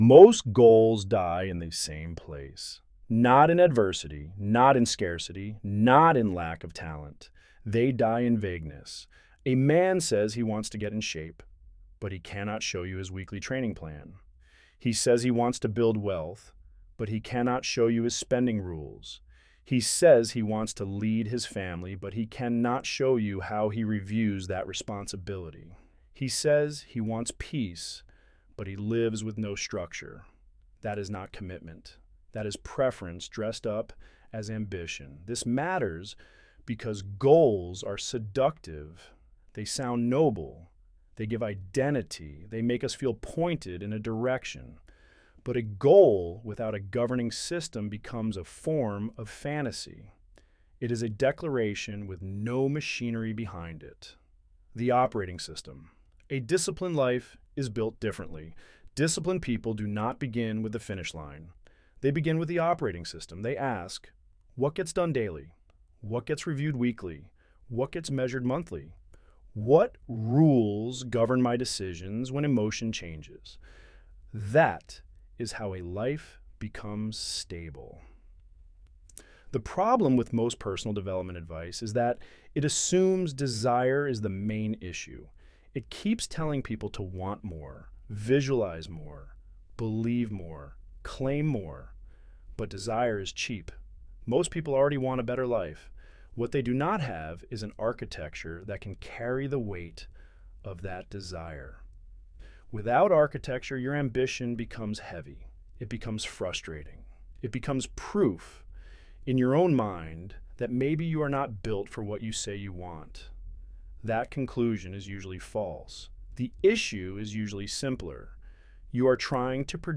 Narrated by the Author Download narration Most goals die in the same place.